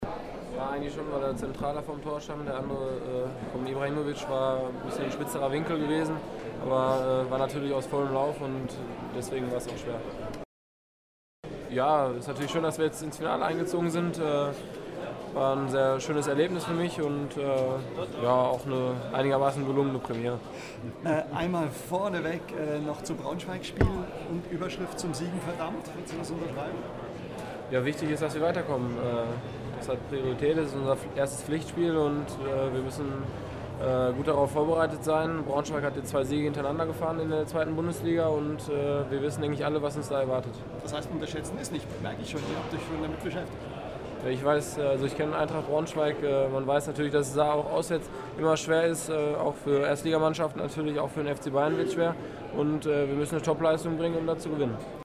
O-Ton-Paket Manuel Neuer